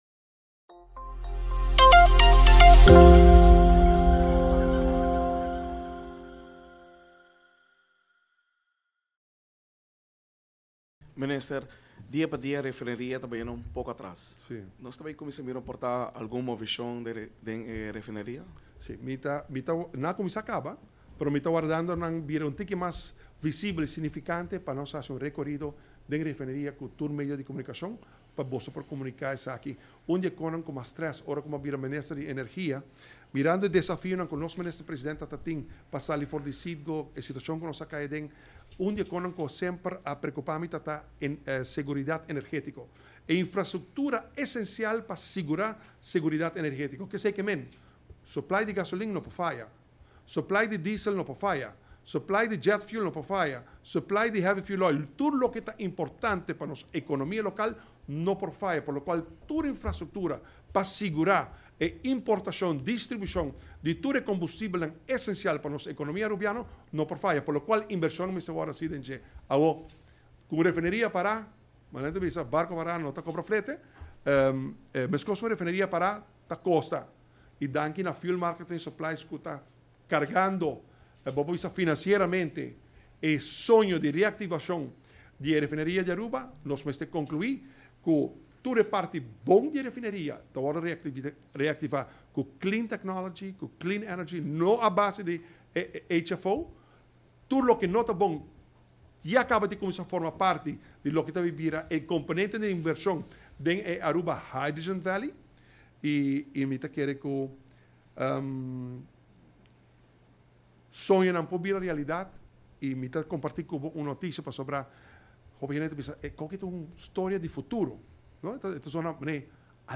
Contestando pregunta di periodista referente e refineria, e mandatario a sigui splica cu mescos cu e dicho ta bisa cu un barco para no ta cobra flete, igual un refineria para no ta genera entrada.